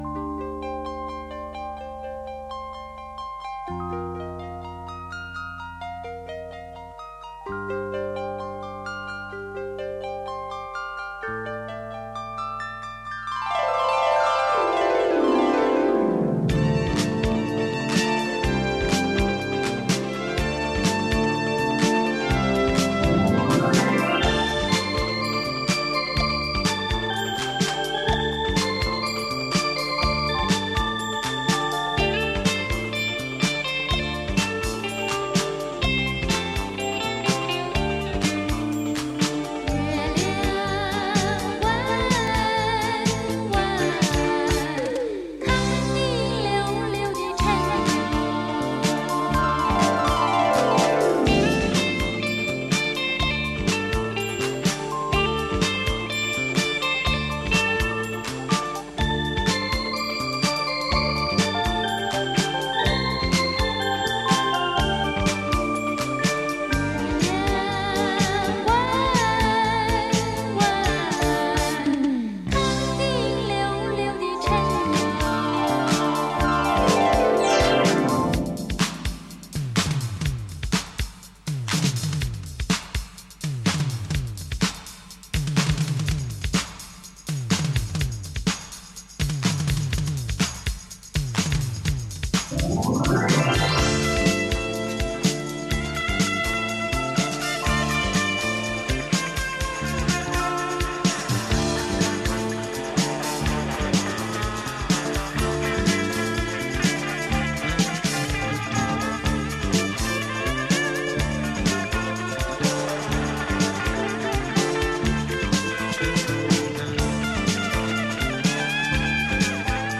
电声大乐队时尚的演奏，再译民歌旋律的新曲风。